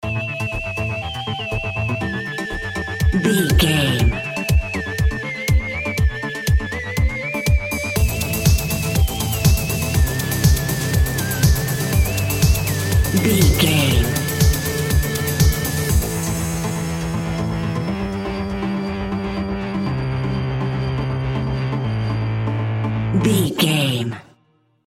Pop Electronic Dance Music 30 Sec.
Fast paced
Aeolian/Minor
Fast
groovy
uplifting
futuristic
driving
energetic
bouncy
synthesiser
drum machine
house
electro dance
synth leads
synth bass
upbeat